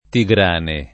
[ ti g r # ne ]